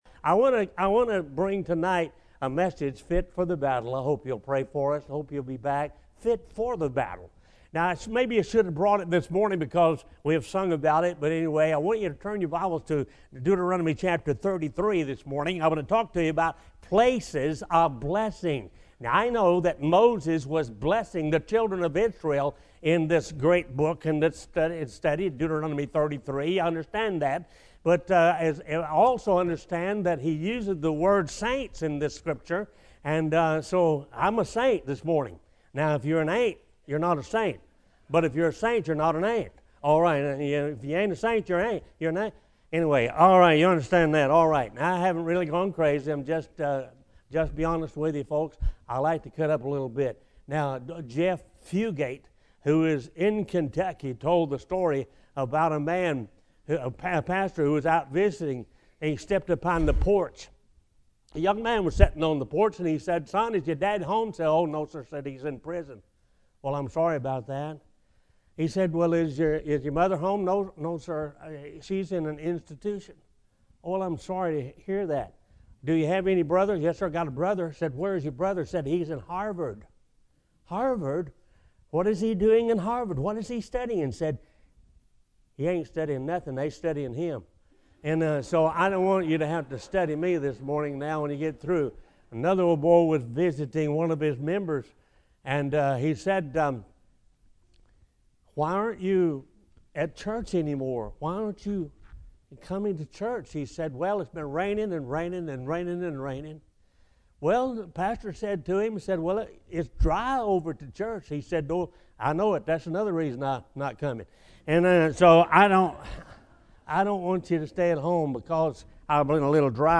Bible Text: Deuteronomy 33 | Preacher